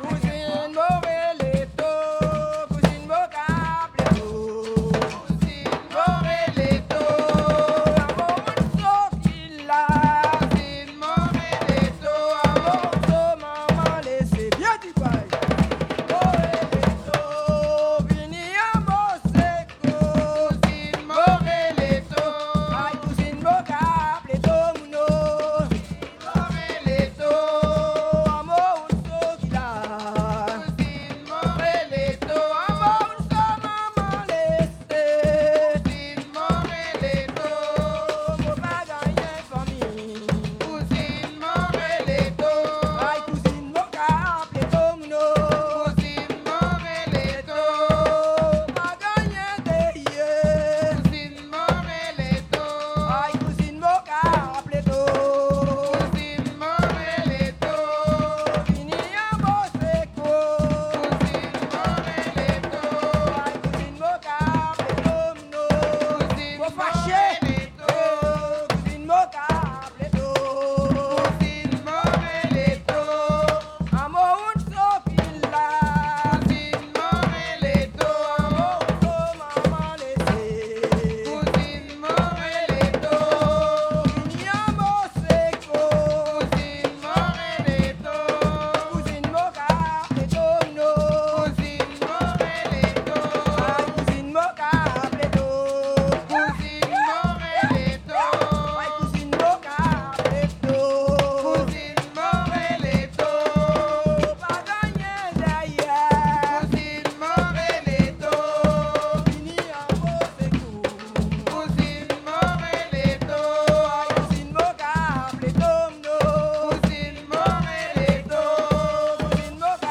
Soirée Mémorial
danse : kasékò (créole)
Pièce musicale inédite